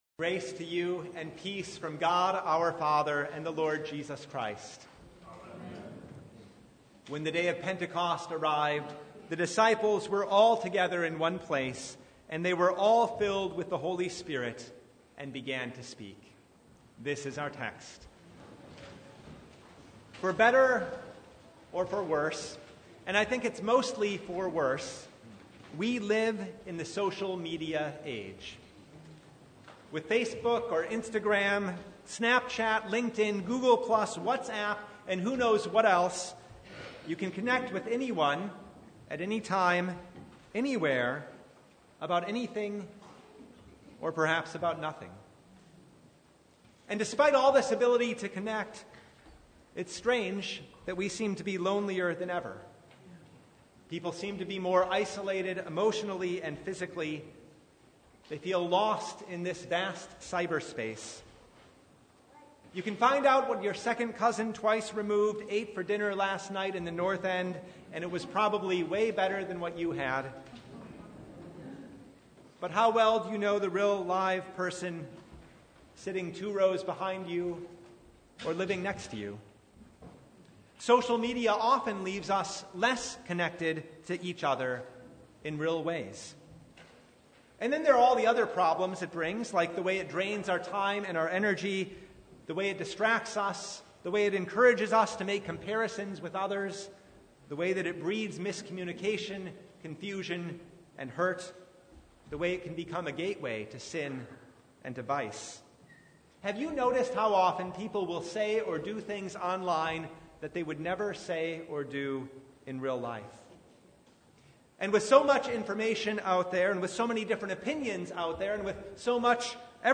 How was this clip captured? Passage: Acts 2:1-21 Service Type: The Feast of Pentecost